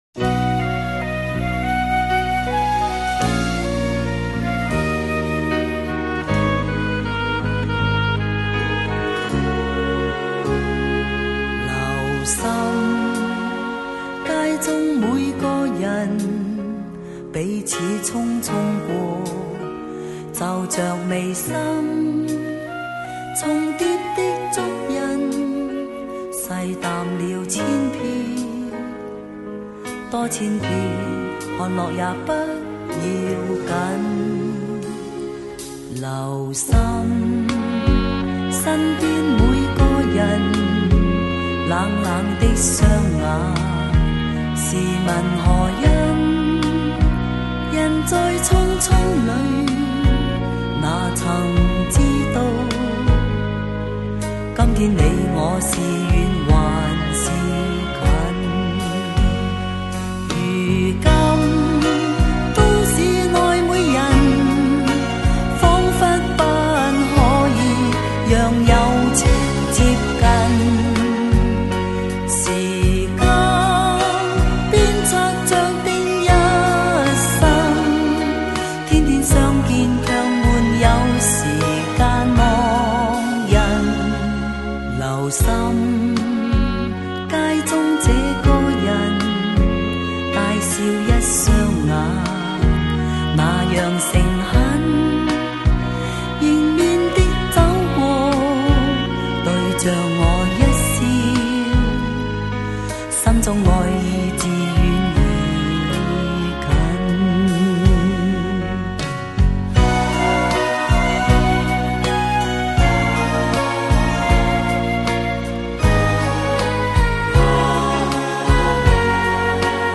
(小插曲：这首歌是我花了上午半天，踏遍百度、谷歌、搜狗寻到的音质较好的单曲，请回复后下载)